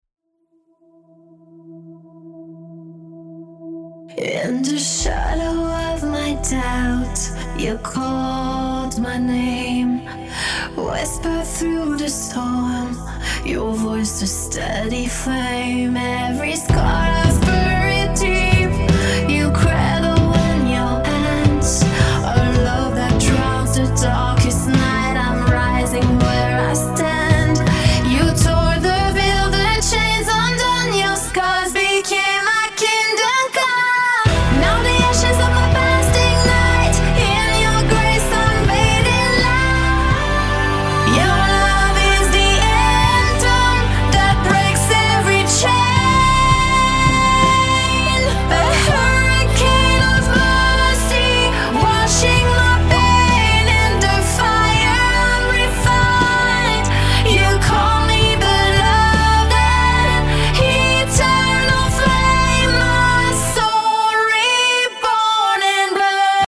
powerful voice